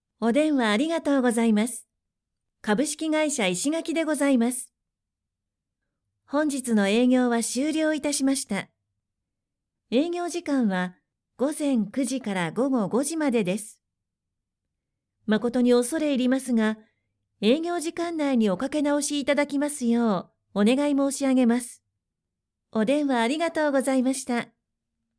クセのない素直な声質で、明るく元気なものから落ち着いたものまで対応可能です。
– ナレーション –
企業の留守番電話
female59_7.mp3